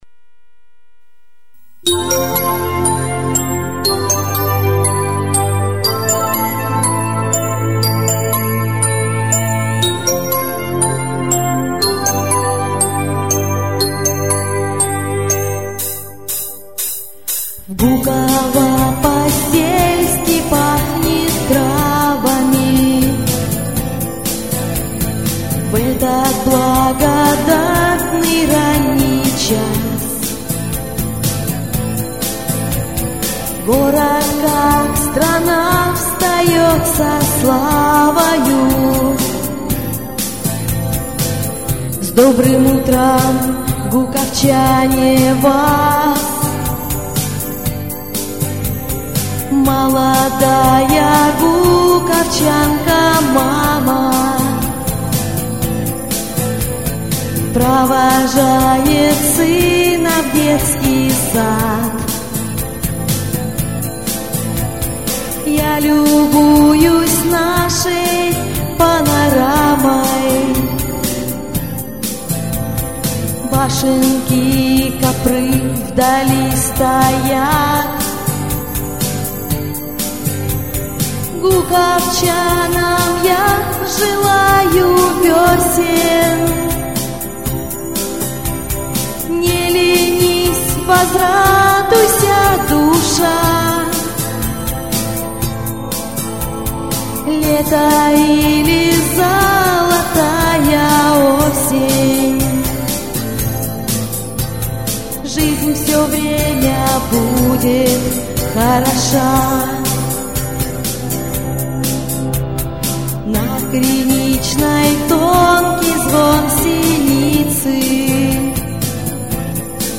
Песня добрая и светлая.